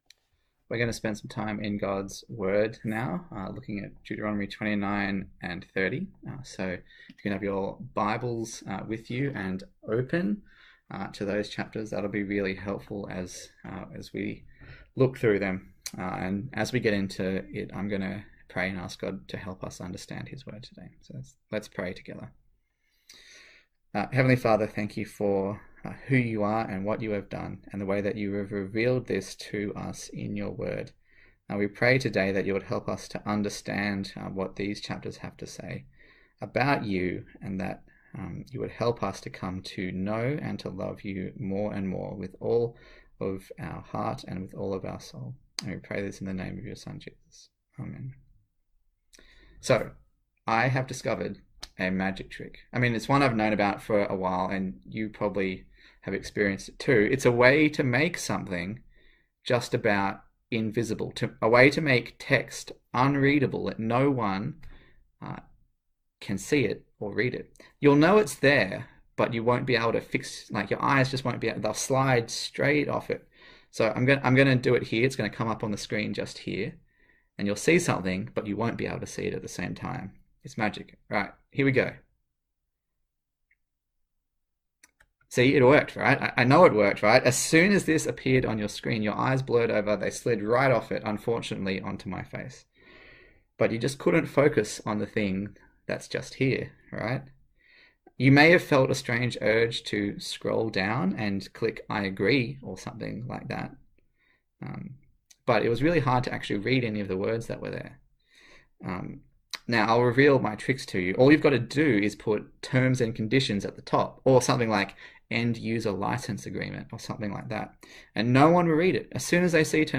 A sermon
Service Type: Sunday Morning